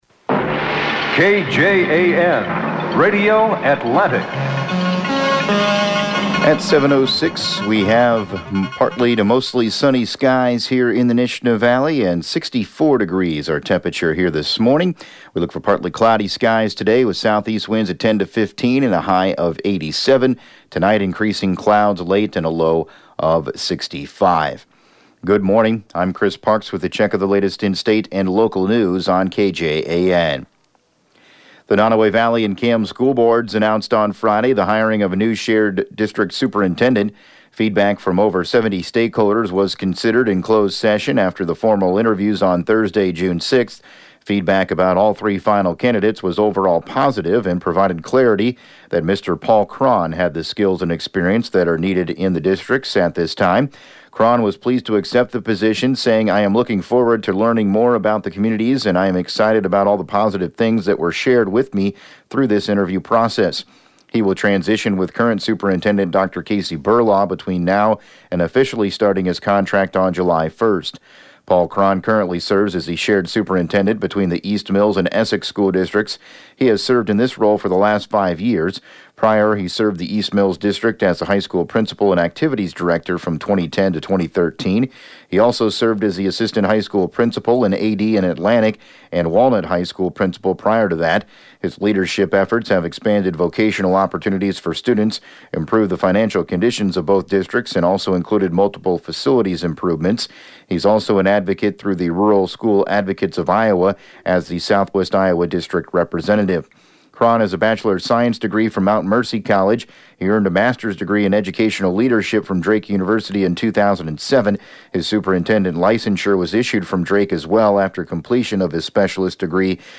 7AM Newscast 06/08/2019